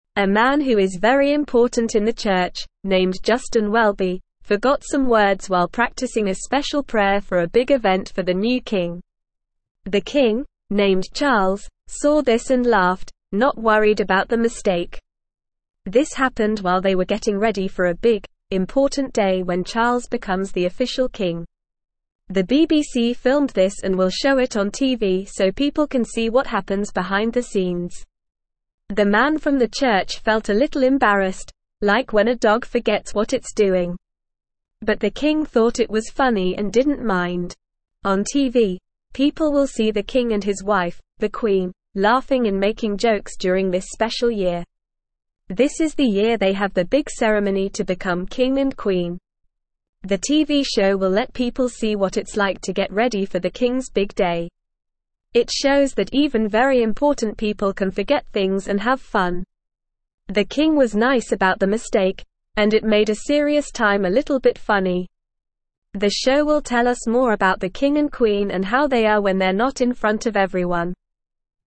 Normal
English-Newsroom-Lower-Intermediate-NORMAL-Reading-The-Kings-Funny-Video-A-Special-Look.mp3